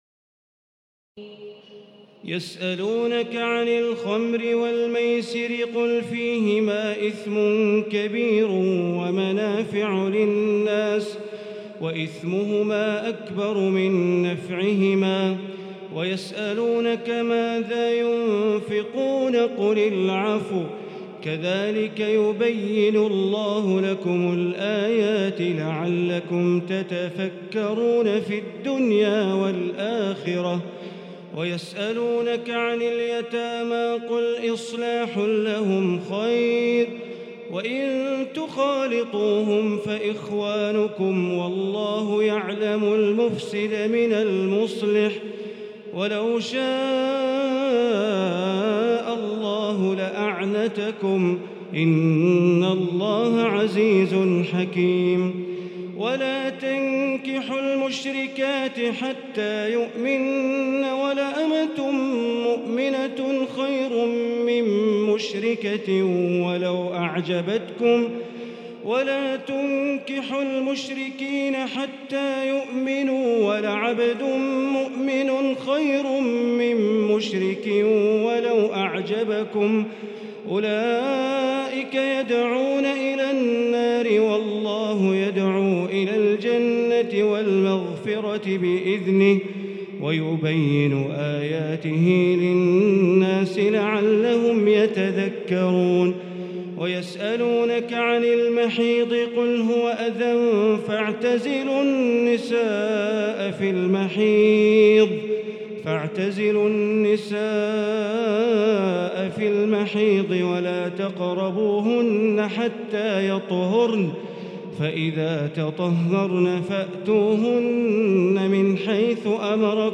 تراويح الليلة الثانية رمضان 1438هـ من سورة البقرة (219-271) Taraweeh 2 st night Ramadan 1438H from Surah Al-Baqara > تراويح الحرم المكي عام 1438 🕋 > التراويح - تلاوات الحرمين